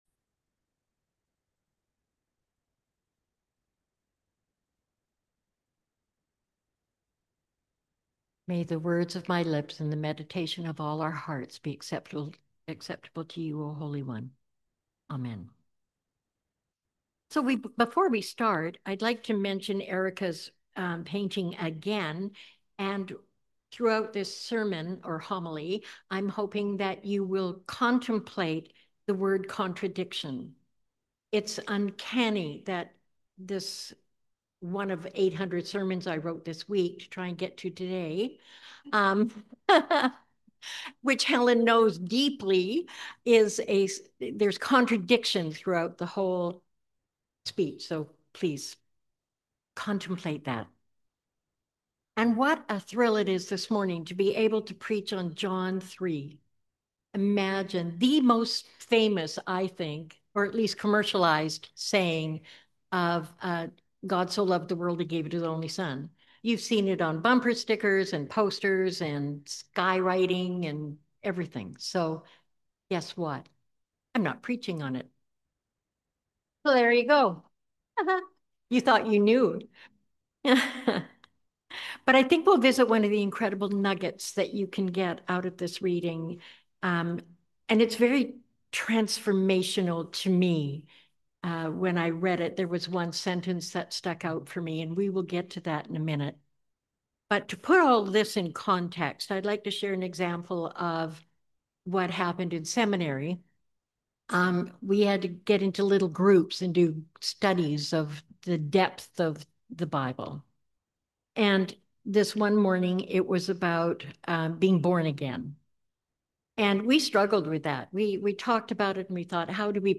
Sermon on the Second Sunday in Lent